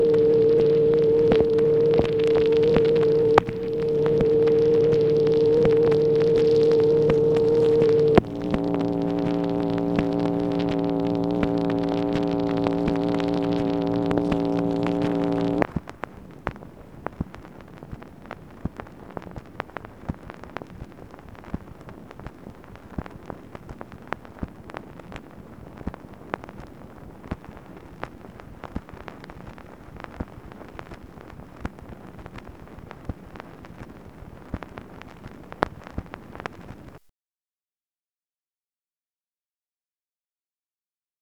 MACHINE NOISE, September 15, 1964
Secret White House Tapes | Lyndon B. Johnson Presidency